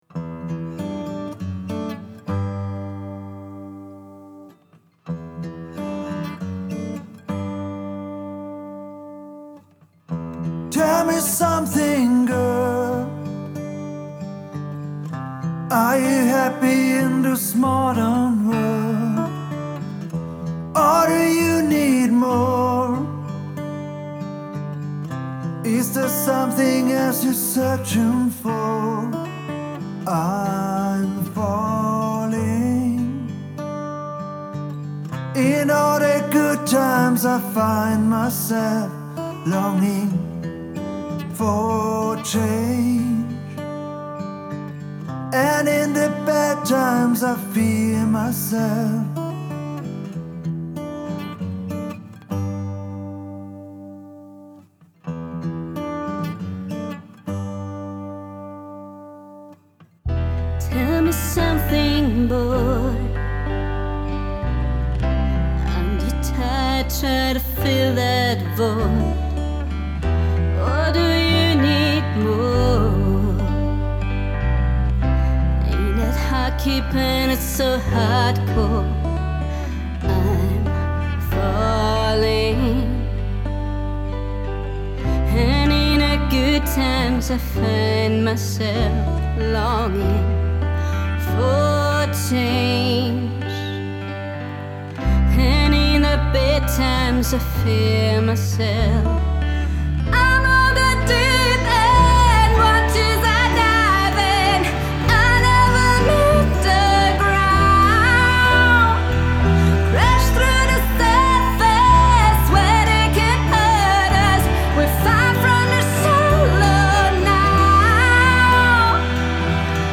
Und zack, bin ich auf -10,6 Lufs Int Außerdem hab ich noch kleine Lautheitskorrekturen gemacht, versucht die Gitarren einigermaßen in Phase zu bringen, Kick und Bass in Time gebracht, Zwei der Gitarrenspuren mit nem De-esser ein bisschen milde gestimmt. Meine Kick klingt superpappig, aber das ist mir jetzt einfach mal Wurst.